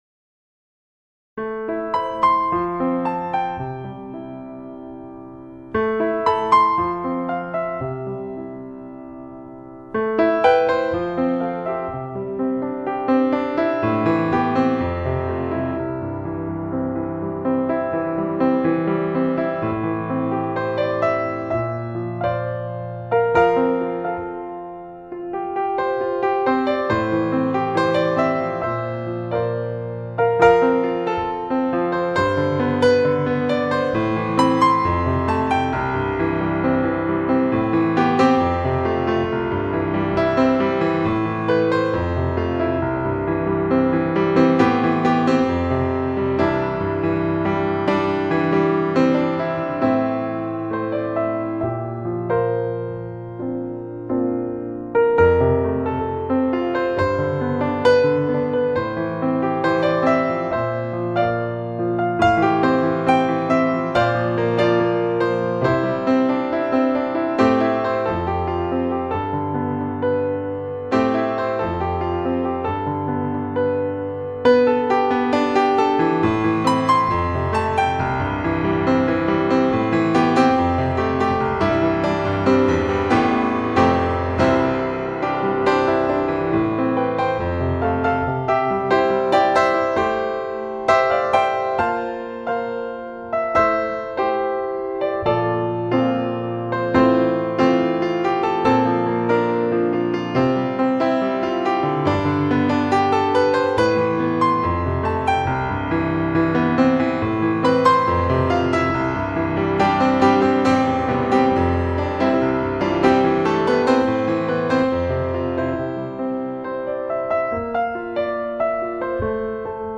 感受自然、清新、脱俗的新世纪音乐.